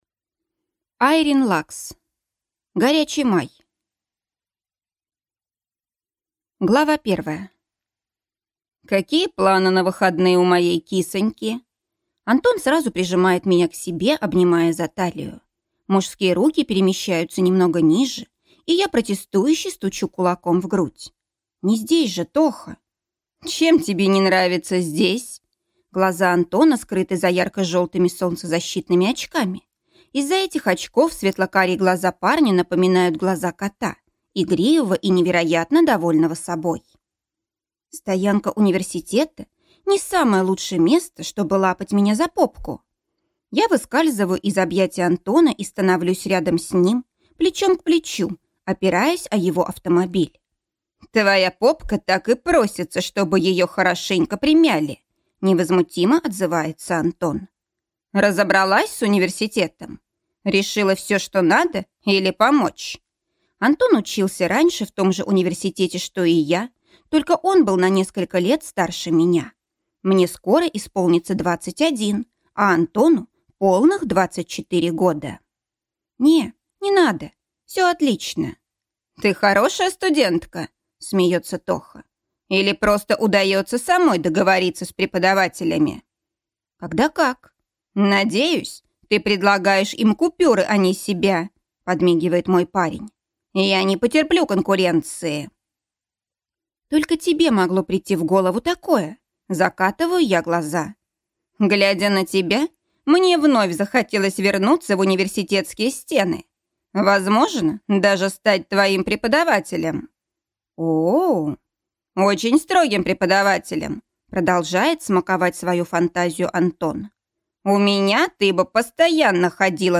Аудиокнига Горячий Май | Библиотека аудиокниг